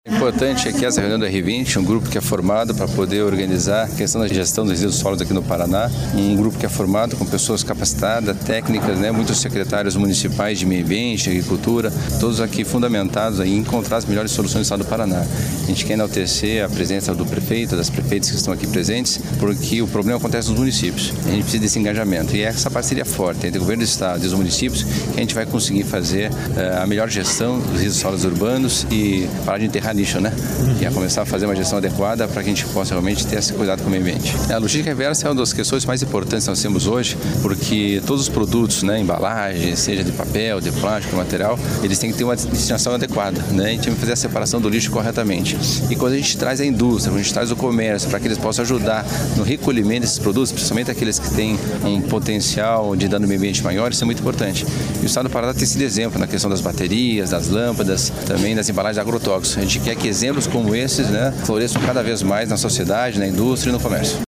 Sonora do secretário de Desenvolvimento Sustentável, Valdemar Bernardo Jorge, sobre o compromisso do Estado e prefeituras com logística reversa e plano de resíduos